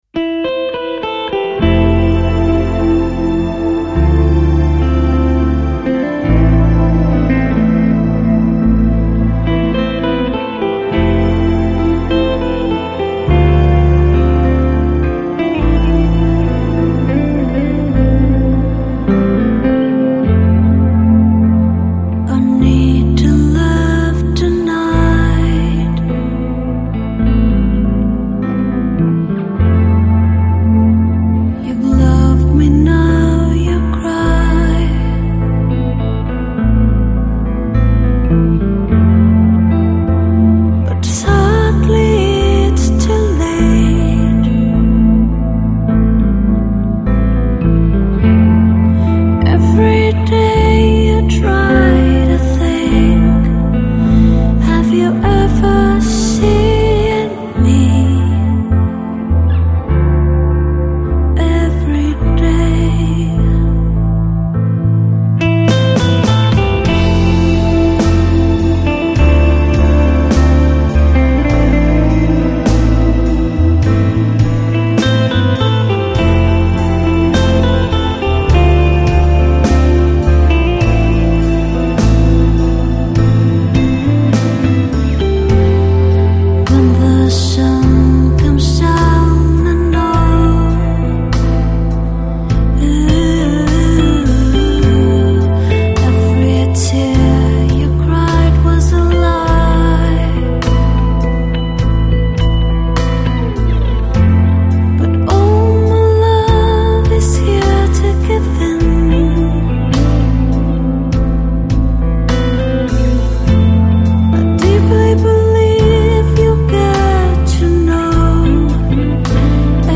Жанр: Electronic, Lounge, Chill Out, Downtempo, World